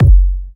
• Round Kick Single Hit F# Key 283.wav
Royality free kick drum tuned to the F# note. Loudest frequency: 107Hz
round-kick-single-hit-f-sharp-key-283-Bis.wav